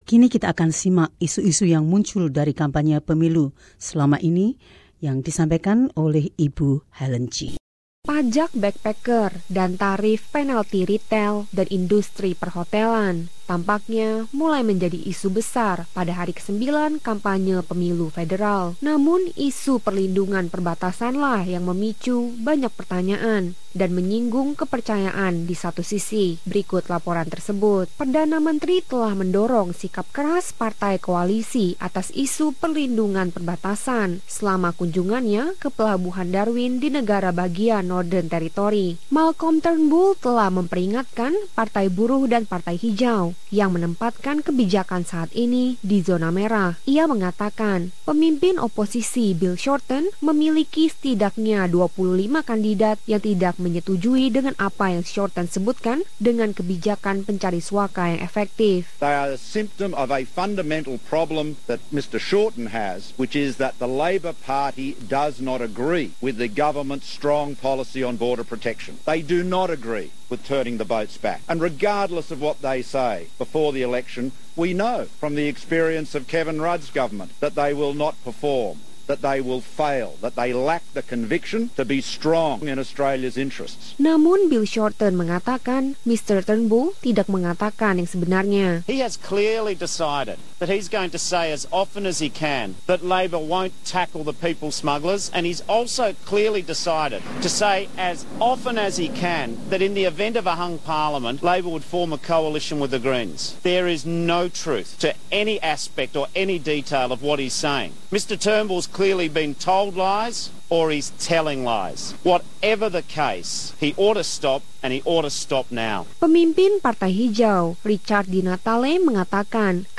Apa fokus dari kampanye pemilu 2016 hari ke 9? Laporan ini menyoroti sikap dari berbagai partai yang berbeda terkait dengan proteksi perbatasan dan apa yang disebut Pajak Backpacker.